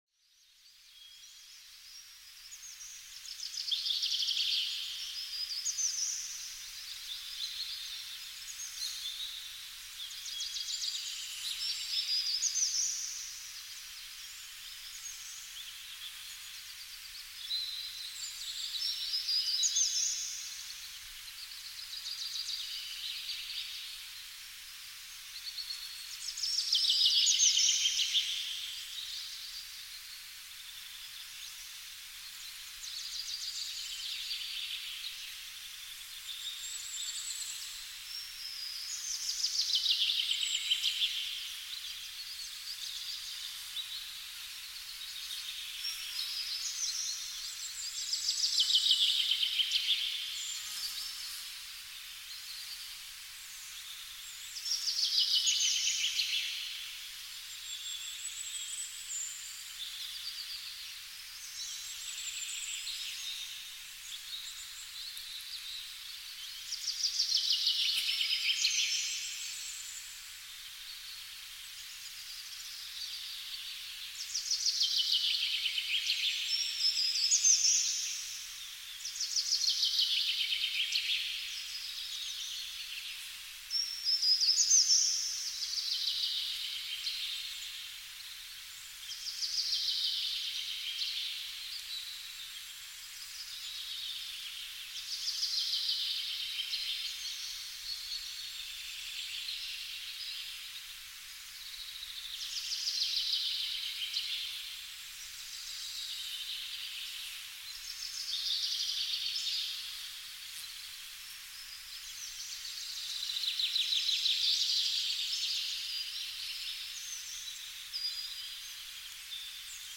Mohnwiese-Morgenlicht: Sanfte Klänge voller entspannender Frieden